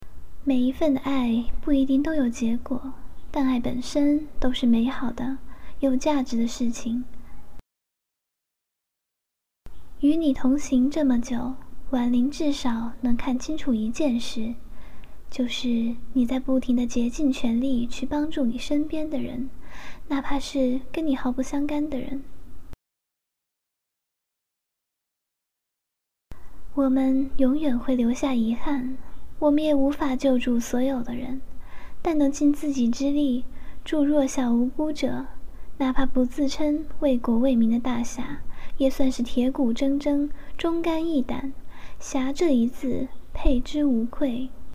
温暖恬静